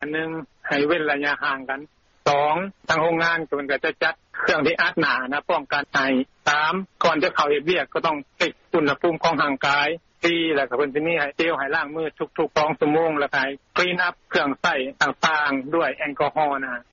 ທີ່ໂຮງງານລົດນິສສັນ